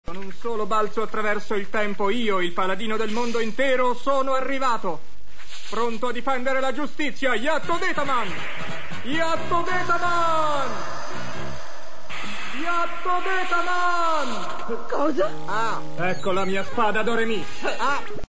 nel cartone animato "Calendar Men", in cui doppia Beppe Domani/Yattodetaman.